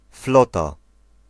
Ääntäminen
Ääntäminen Tuntematon aksentti: IPA: /ˈflɔta/ Haettu sana löytyi näillä lähdekielillä: puola Käännös Konteksti Ääninäyte Substantiivit 1. naval fleet 2. money slangi, leikkisä UK UK US 3. fleet UK US Suku: f .